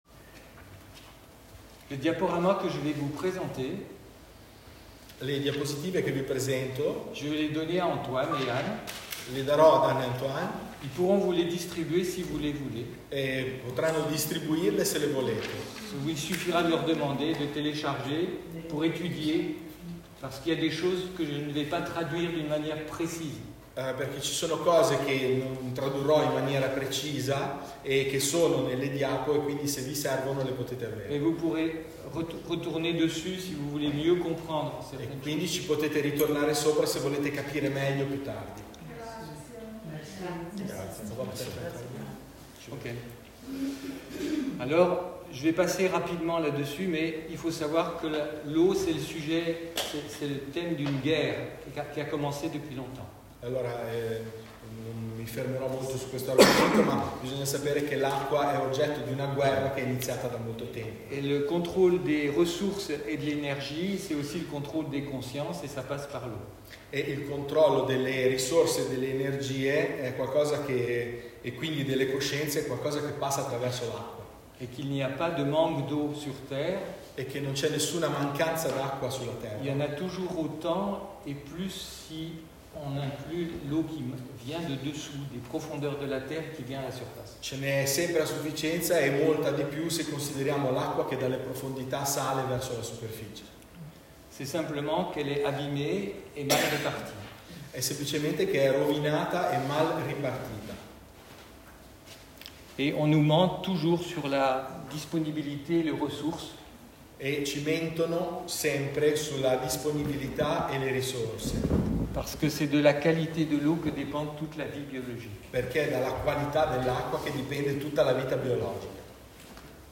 Lors de la réunion des Thérapeutes, au Peuch.